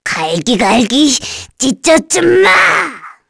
Nia-Vox_Skill6_kr.wav